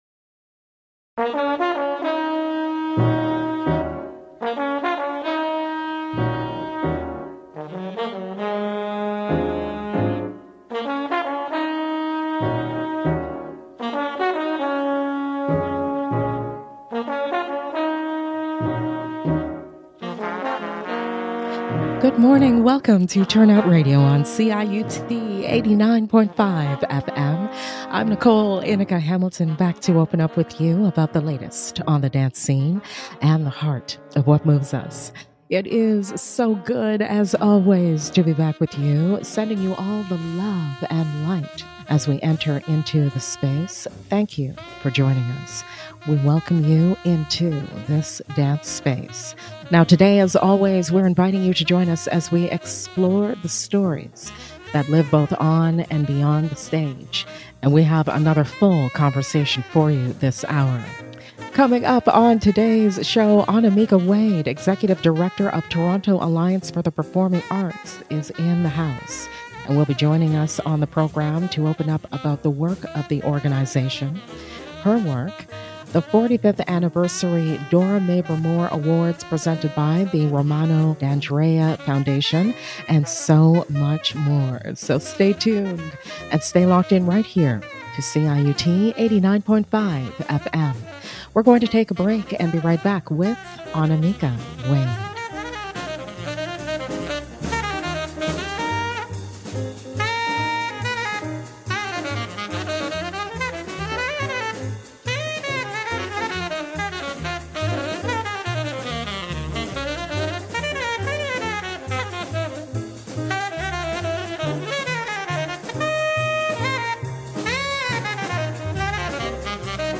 ive on CIUT 89.5 FM